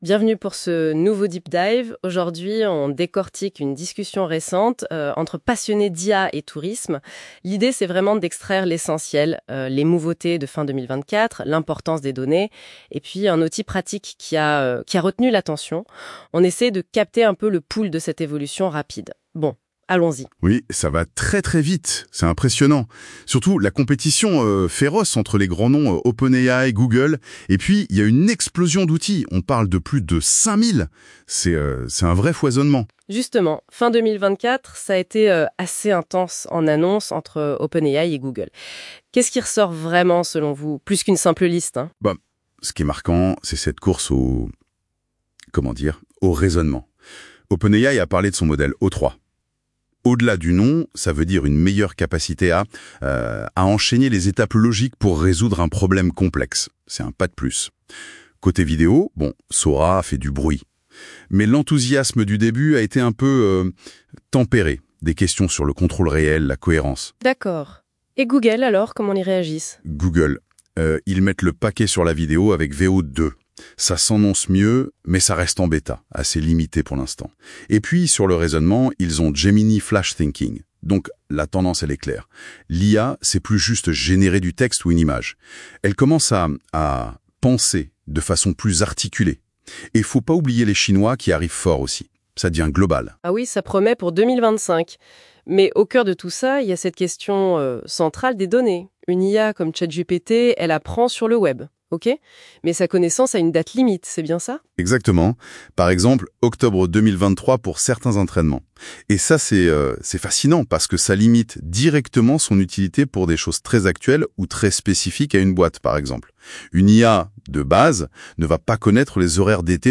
L'interview IA